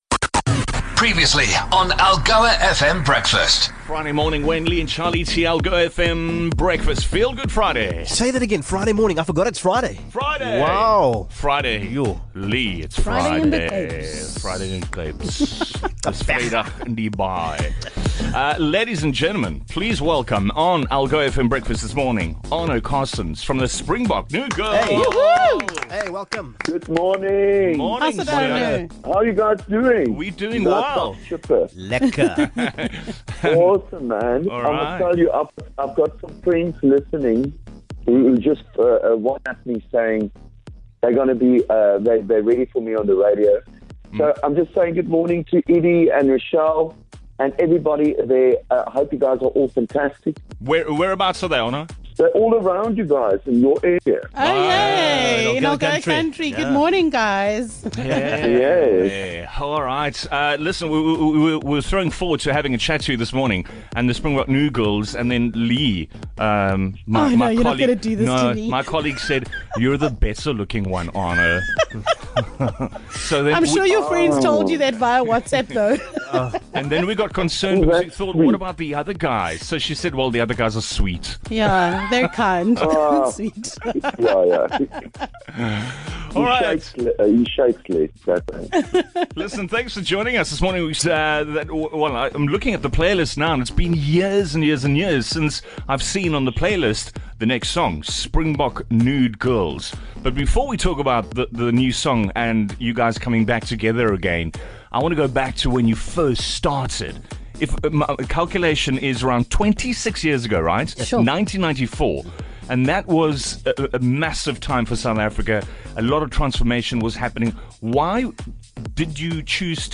2 Jul Arno Carstens live on Breakfast in celebration of new single